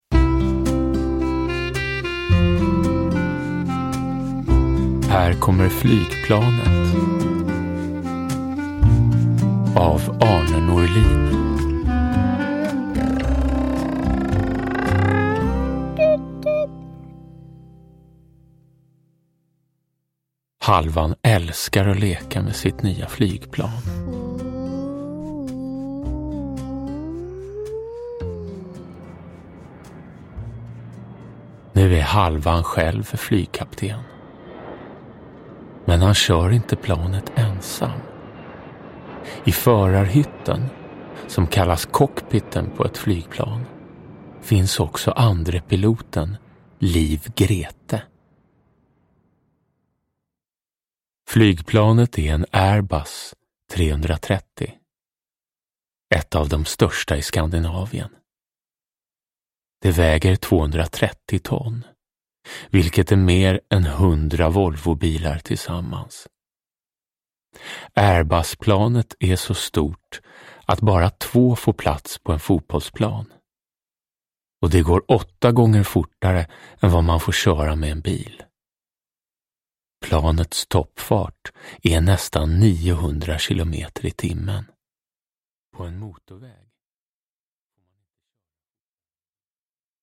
Här kommer flygplanet – Ljudbok – Laddas ner
Uppläsare: Jonas Karlsson